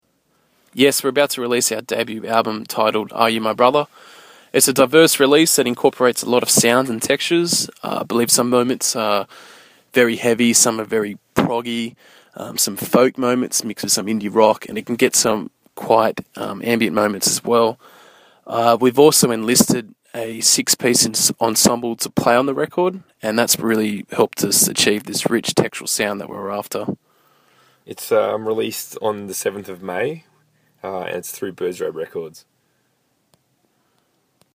SOLKYRI INTERVIEW – May 2013